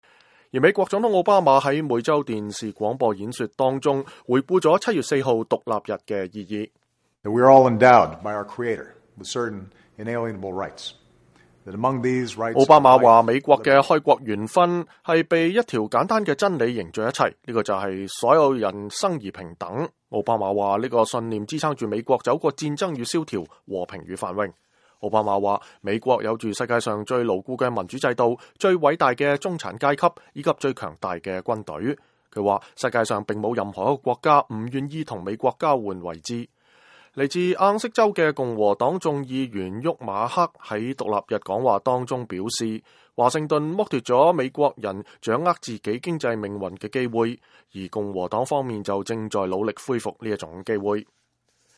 美國總統奧巴馬在每週電視廣播演說當中回顧了7月4號獨立日的意義。他說，美國的開國元勳們是被一條簡單的真理凝聚在一起的，這就是所有的人生而平等。